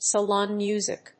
アクセントsalón mùsic